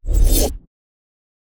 cast-generic-03.ogg